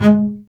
STR BASS M20.wav